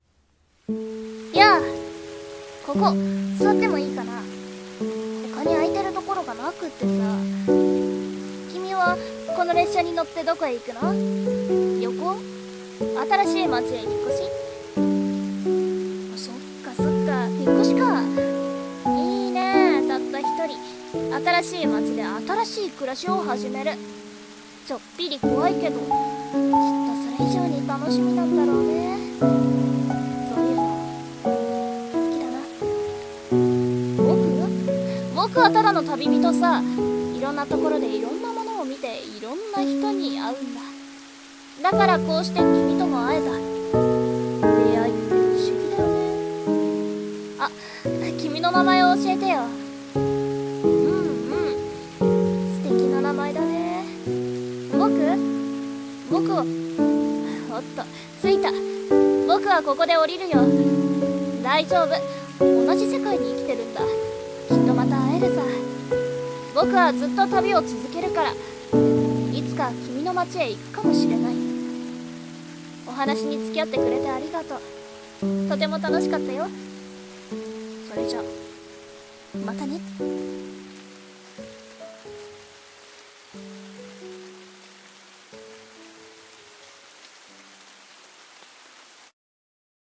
声劇「みしらぬネコ